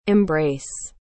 Abaixo, separamos as principais palavras, seus significados e a pronúncia para você ouvir e praticar a fala.